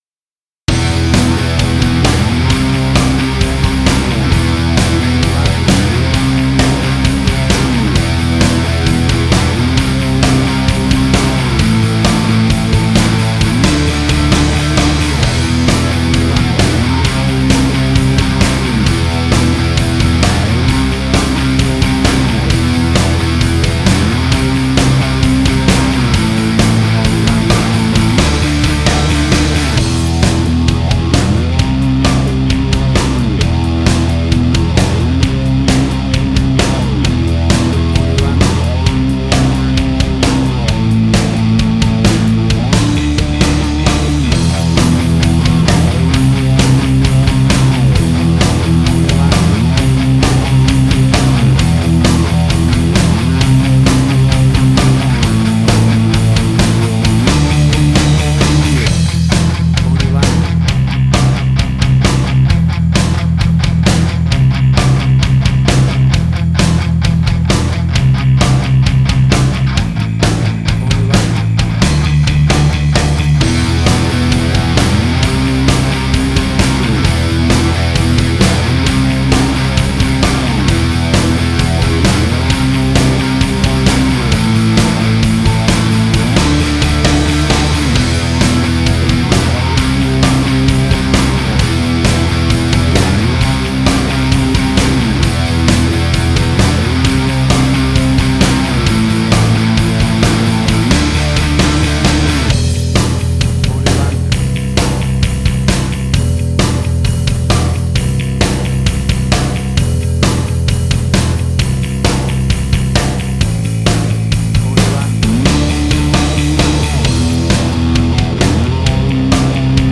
Hard Rock, Similar Black Sabbath, AC-DC, Heavy Metal.
Tempo (BPM): 132